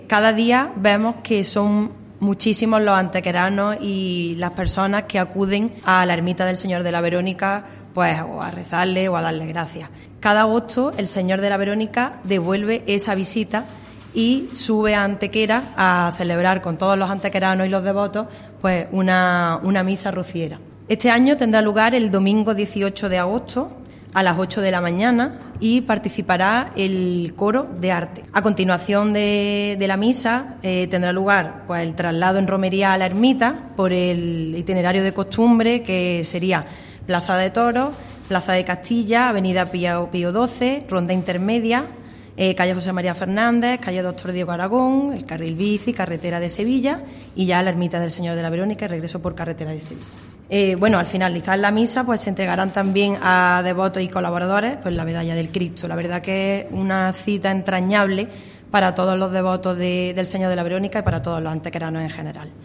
"Cada día vemos que son muchísimos los antequeranos que acuden a la ermita del Señor a rezarle o darle las gracias; cada agosto, el Señor de la Verónica devuelve esa visita y sube a Antequera a celebrar con todos los antequeranos y los devotos una misa rociera", manifestaba durante la rueda de prensa la teniente de alcalde Elena Melero, calificando esta cita anual como "entrañable" para todos los antequeranos en general.
Cortes de voz